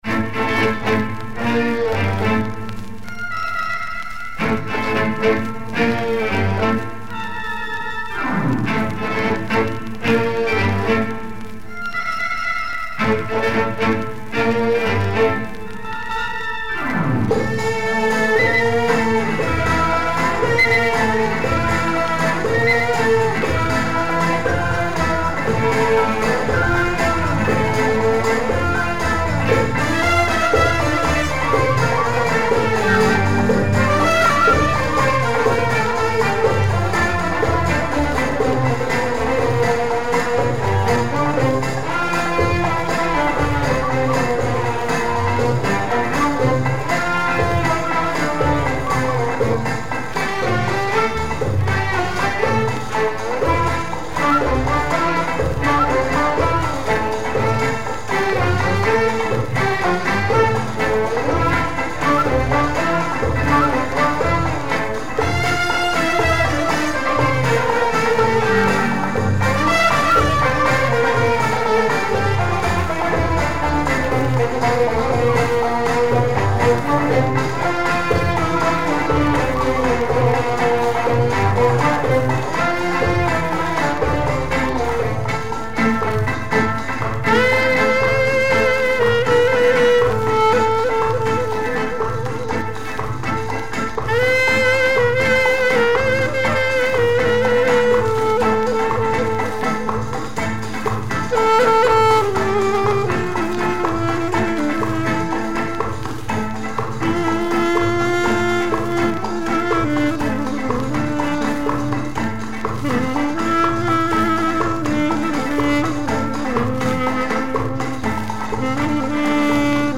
Killer Arabic beats.